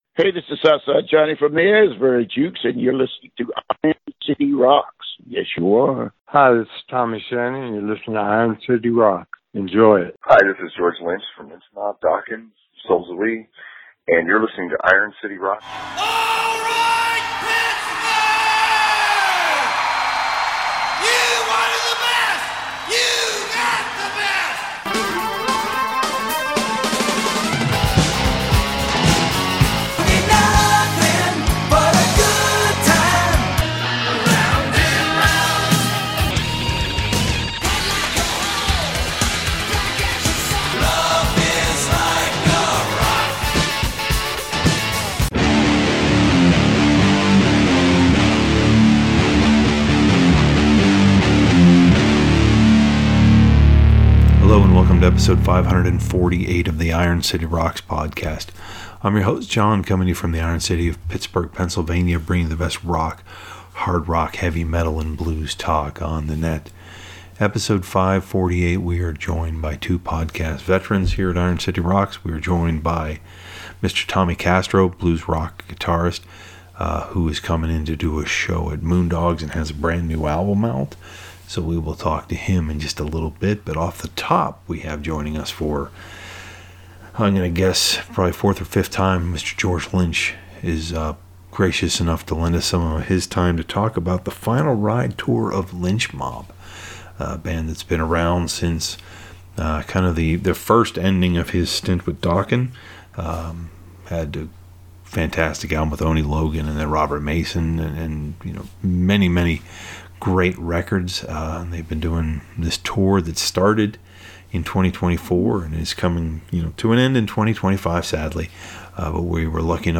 We are then joined by bluesman Tommy Castro talking about his new album Closer to the Bone on Alligator Records.